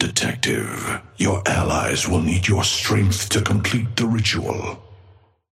Amber Hand voice line - Detective. Your allies will need your strength to complete the ritual.
Patron_male_ally_atlas_start_05.mp3